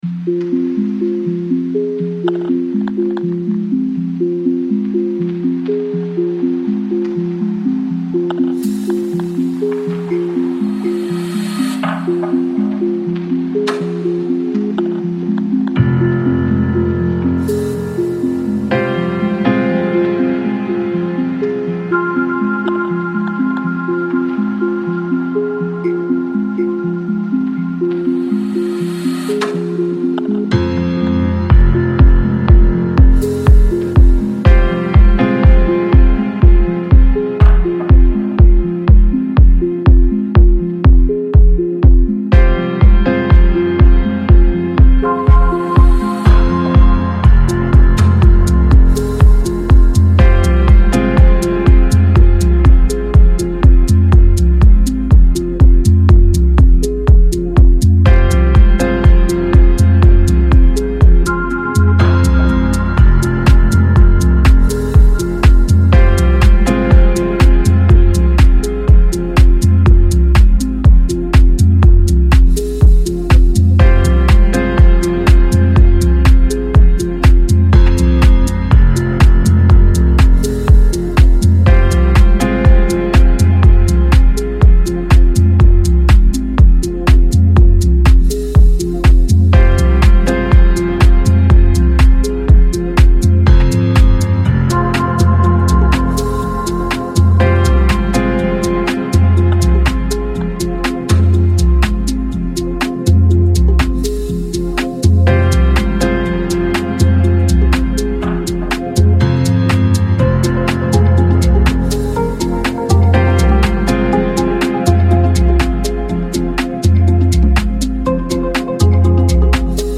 Progressive and Deep House